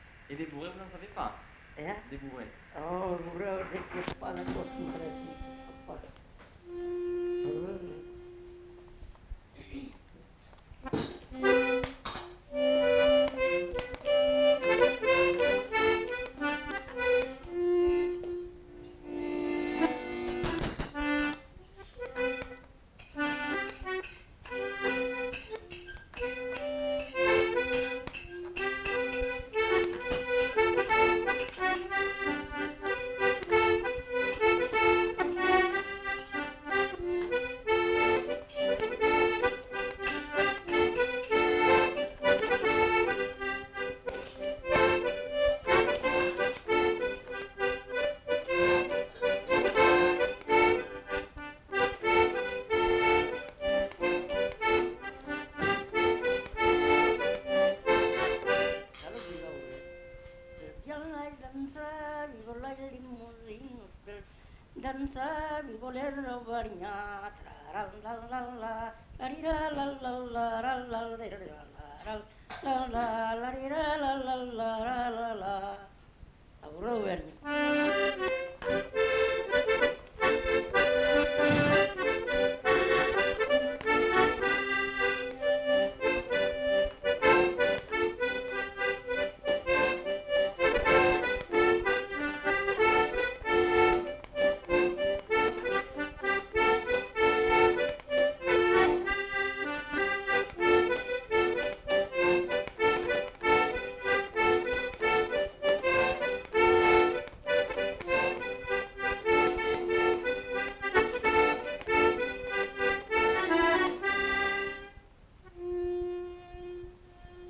Genre : morceau instrumental
Instrument de musique : accordéon diatonique
Danse : bourrée
Notes consultables : Chante le couplet à danser.
Ecouter-voir : archives sonores en ligne